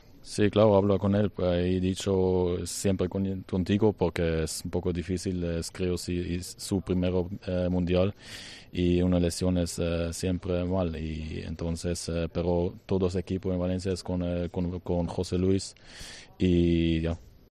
Eray Cömert atendió a los medios en la zona mixta en español y dedicó también unas palabras al capitán del Valencia CF, José Luis Gayà: "Hablé con él. Le he dicho que estoy siempre con él. Es un poco difícil en su primer Mundial lo que ha pasado y una lesión es siempre mala noticia. Todo el equipo está con José Luis".